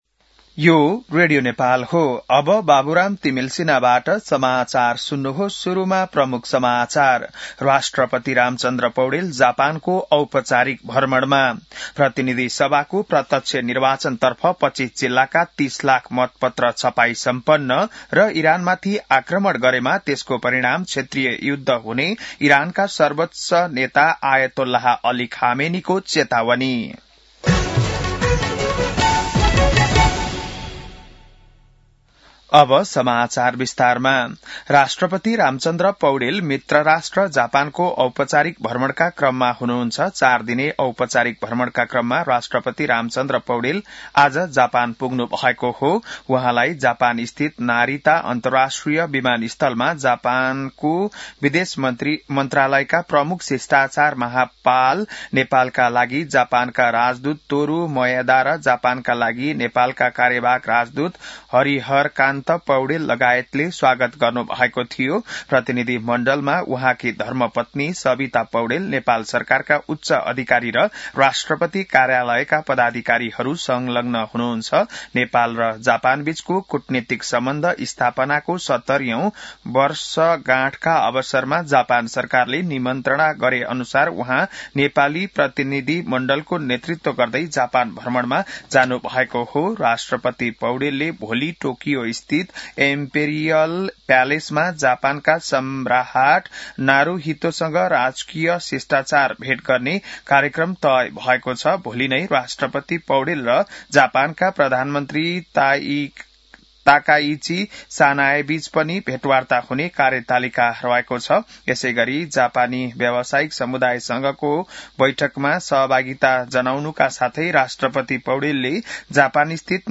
बिहान ९ बजेको नेपाली समाचार : १९ माघ , २०८२